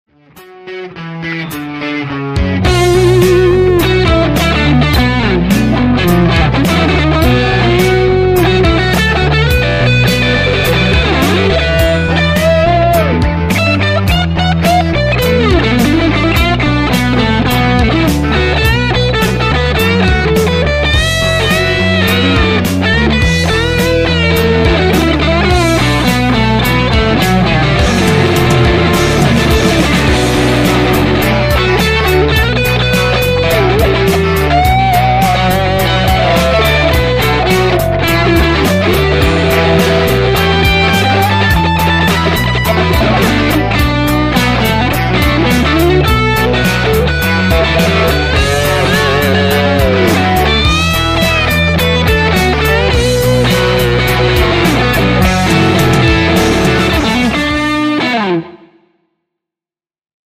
- soita soolosi annetun taustan päälle
- taustan tulee olla sointukierroltaan blues (I-IV-V)- sointuja ja tyyliä soveltaen.
hyvää energiaa 1 p
Rajua revittelyä. Hyvä meininki.